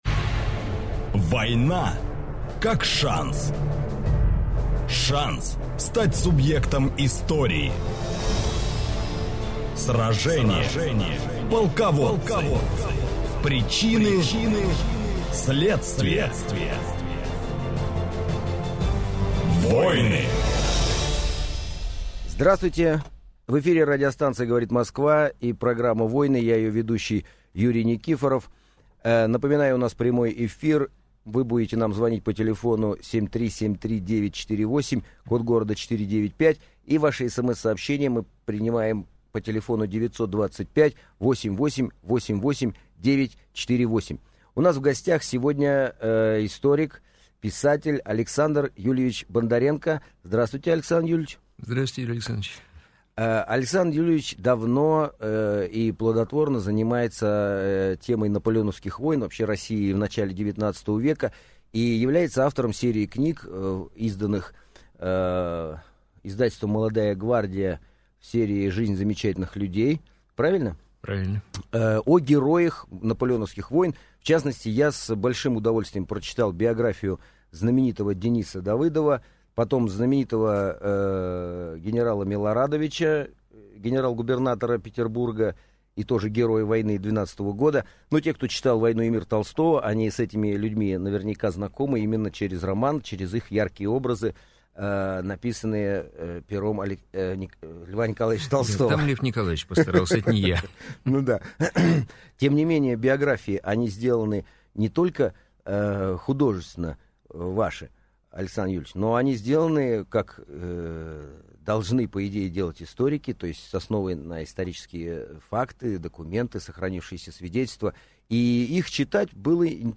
Аудиокнига Герои Отечественной войны 1812 года | Библиотека аудиокниг